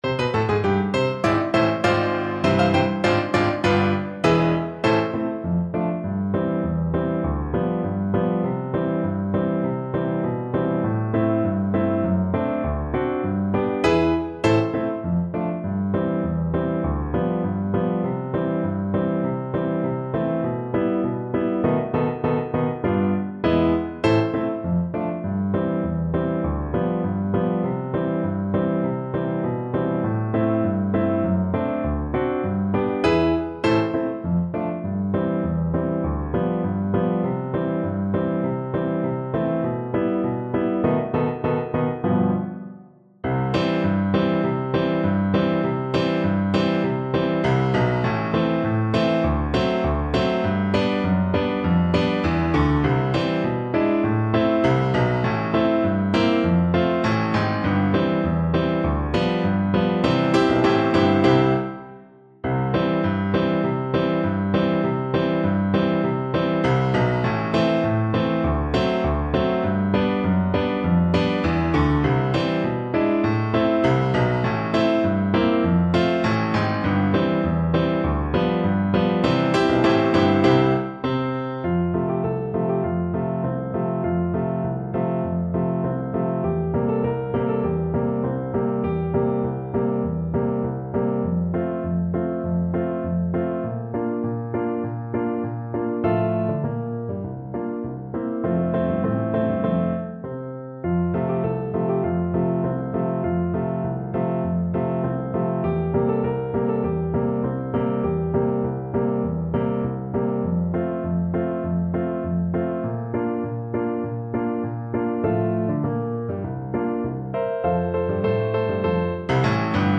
Classical Sousa, John Philip Hands Across the Sea March Tuba version
Play (or use space bar on your keyboard) Pause Music Playalong - Piano Accompaniment Playalong Band Accompaniment not yet available transpose reset tempo print settings full screen
Tuba
C major (Sounding Pitch) (View more C major Music for Tuba )
2/2 (View more 2/2 Music)
Classical (View more Classical Tuba Music)